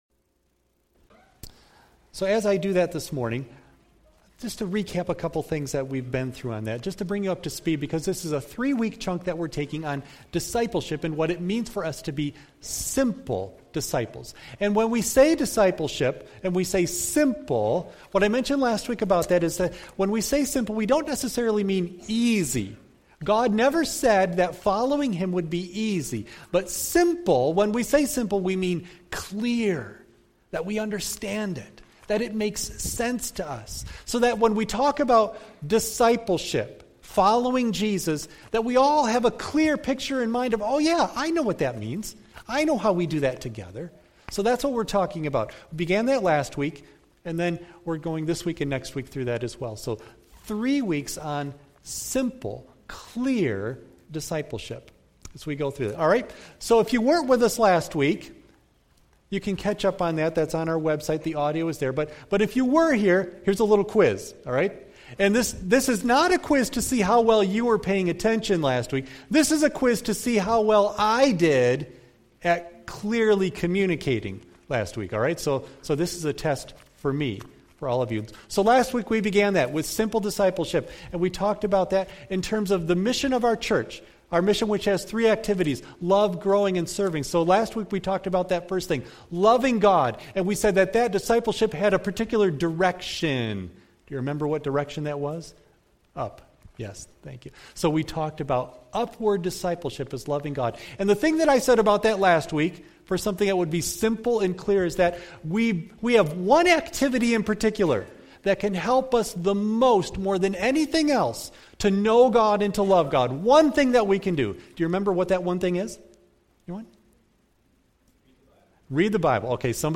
Hebrews 10:19-25 Service Type: Sunday AM Bible Text